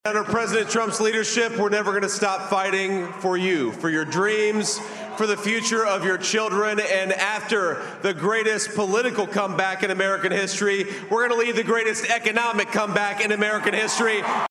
NOW “PRESIDENT ELECT” DONALD TRUMP’S RUNNING MATE, OHIO SENATOR J-D VANCE ADDRESSED SUPPORTERS AT THE TRUMP ELECTION NIGHT WATCH PARTY IN WEST PALM BEACH…